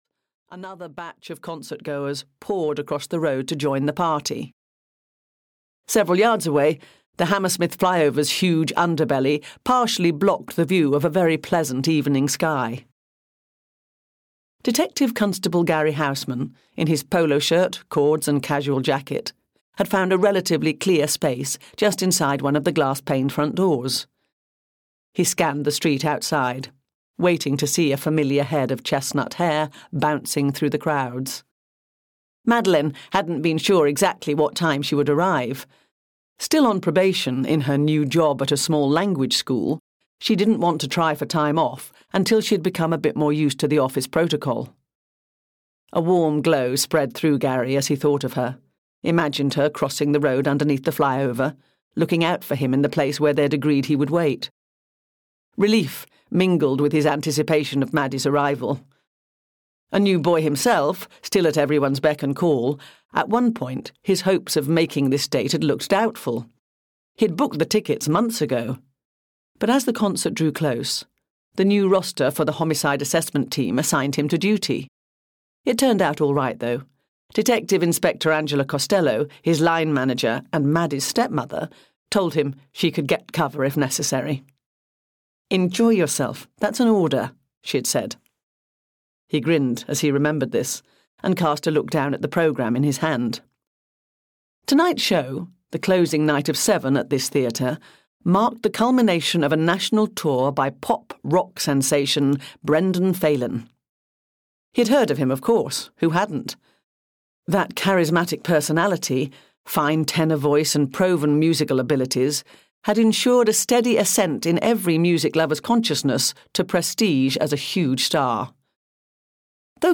Audio knihaEnd of the Roadie (EN)
Ukázka z knihy